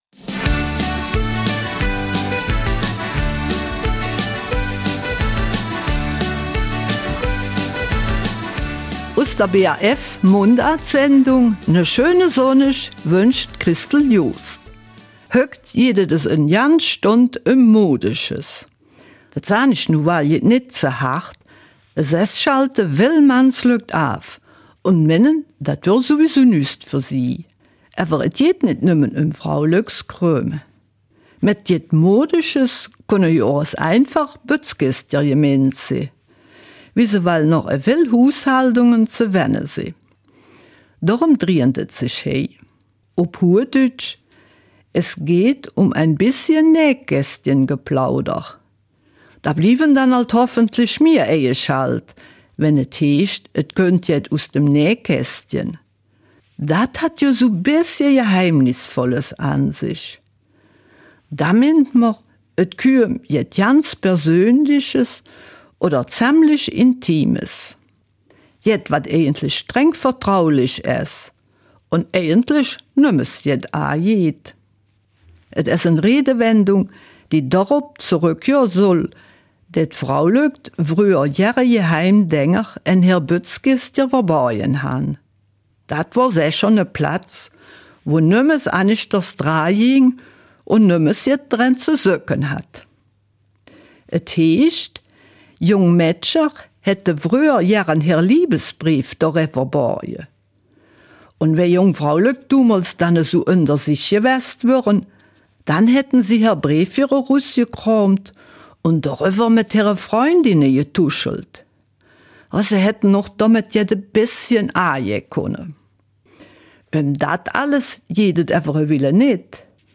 In der Mundartsendung vom 30. November geht es während einer Stunde rund ums Nähkästchen. Jedoch nicht nur um Geplauder aus demselben, sondern eher darum, welche Schätzchen sich darin befinden und wofür sie auch heute noch zu gebrauchen sind.